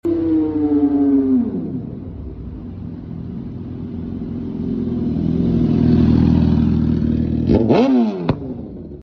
Gsxr 750 Rolling Shot ! Sound Effects Free Download